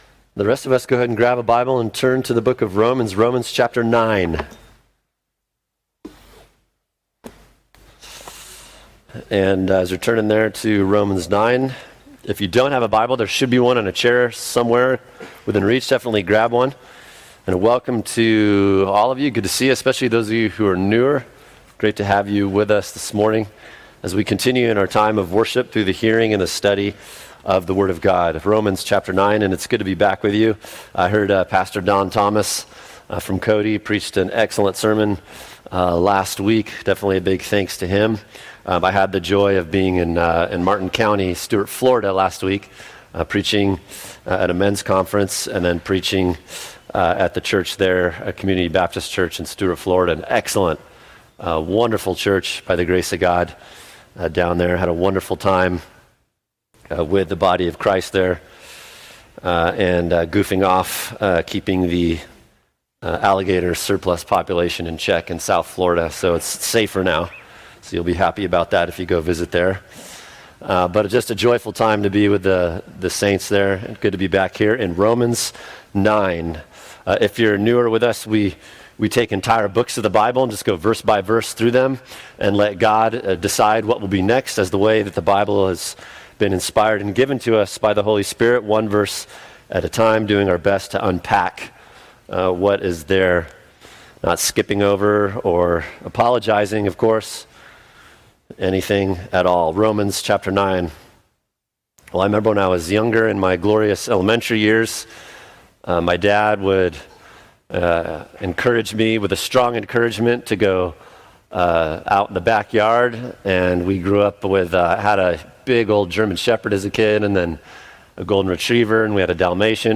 [sermon] Is Predestination Unfair?